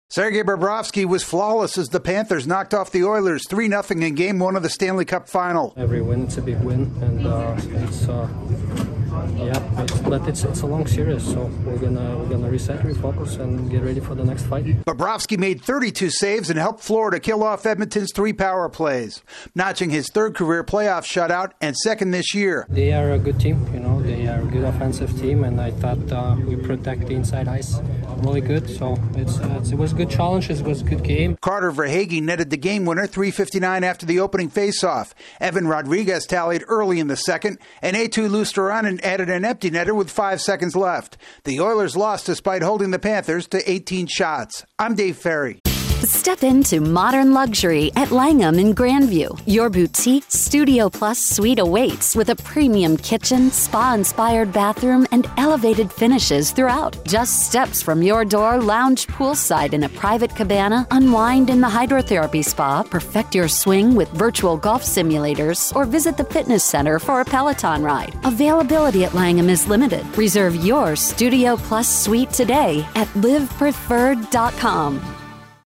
The Panthers are three wins away from their first Stanley Cup title. AP correspondent